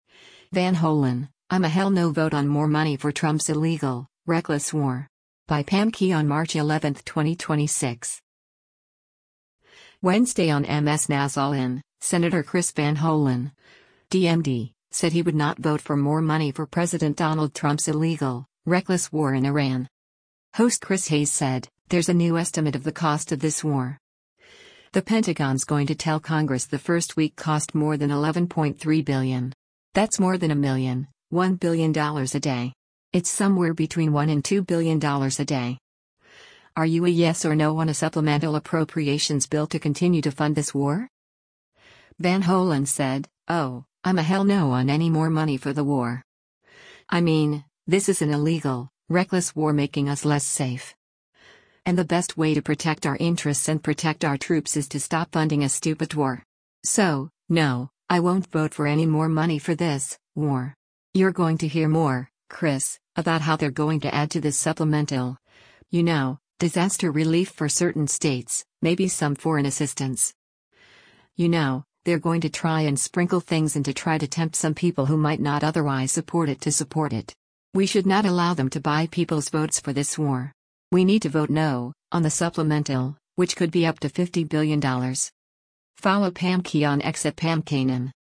Wednesday on MS NOW’s “All In,” Sen. Chris Van Hollen (D-MD) said he would not vote for more money for President Donald Trump’s “illegal, reckless war” in Iran.